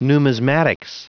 Prononciation du mot numismatics en anglais (fichier audio)
numismatics.wav